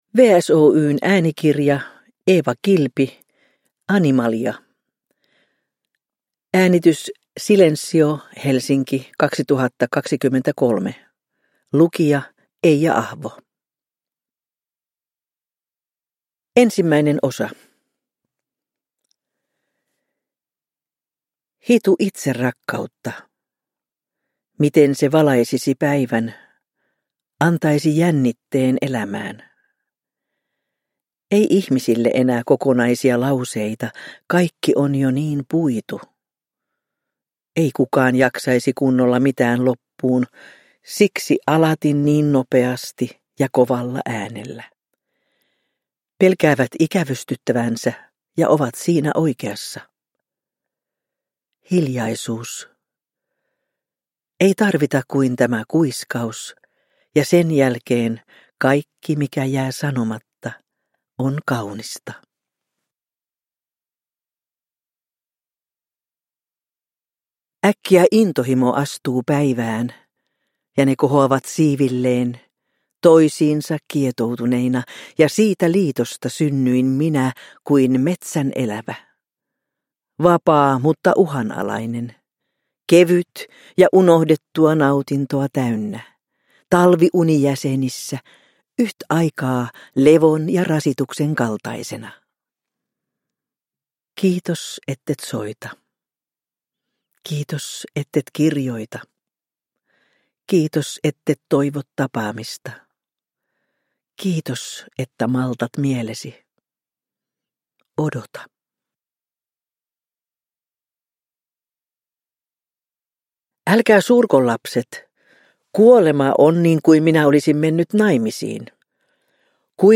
Uppläsare: Eija Ahvo